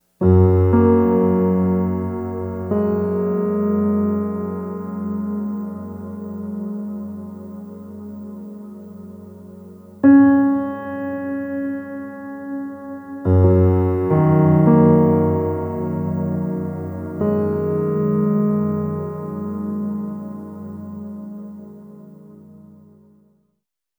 Reverb Piano 03.wav